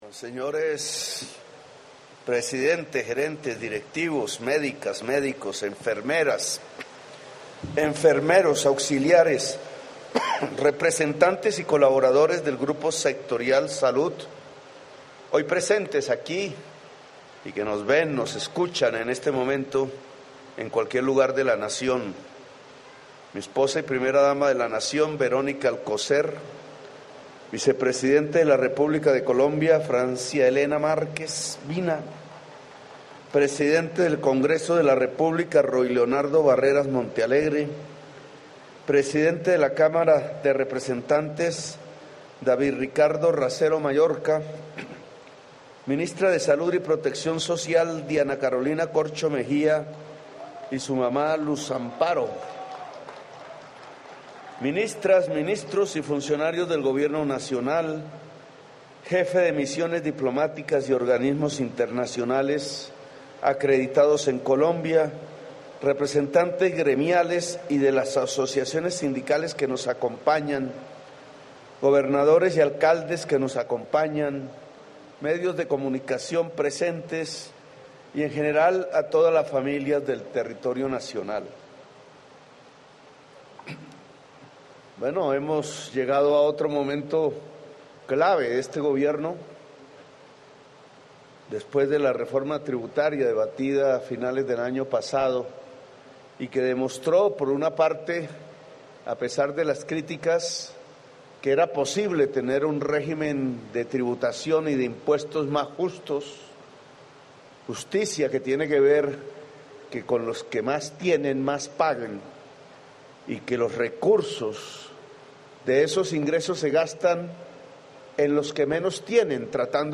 Palabras del Presidente Gustavo Petro, durante el acto simbólico de radicación del Proyecto de Ley de cambio hacia una salud para la vida